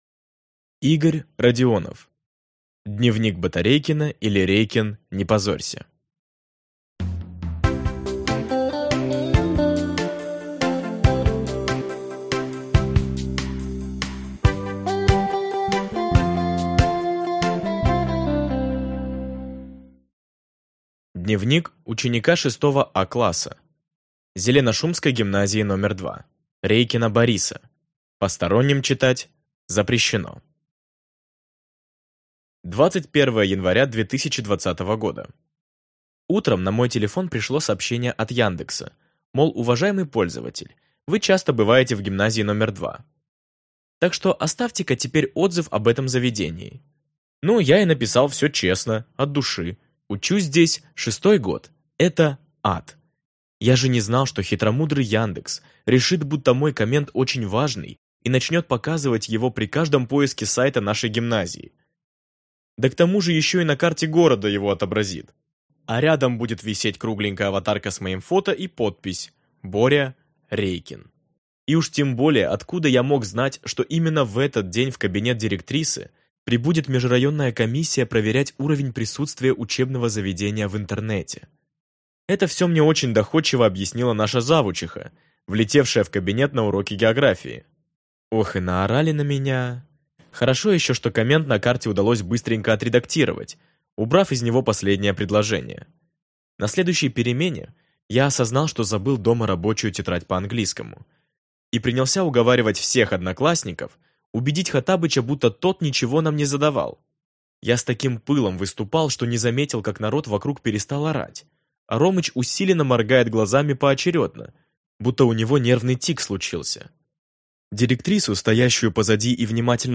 Аудиокнига Дневник Батарейкина, или Рейкин, не позорься!